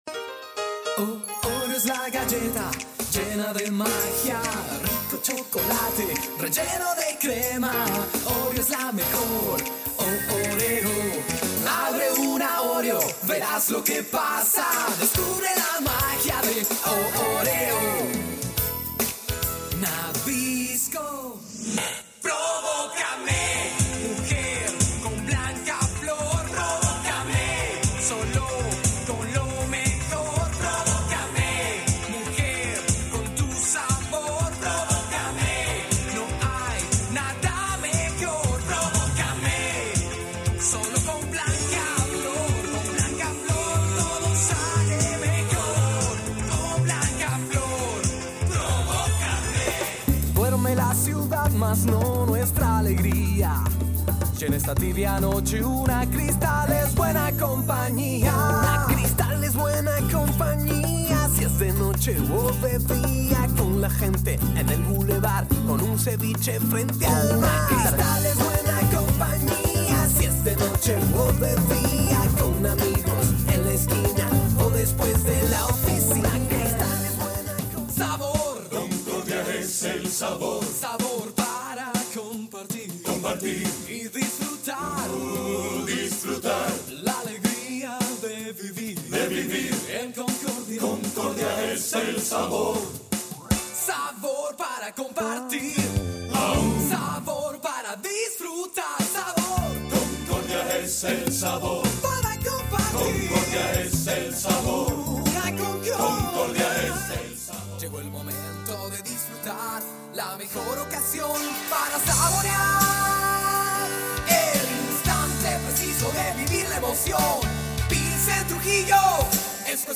Voz versátil, cálida y de amplio rango vocal.
Sprechprobe: Sonstiges (Muttersprache):
Versatile, warm voice with a wide vocal range.
Professional recording studio.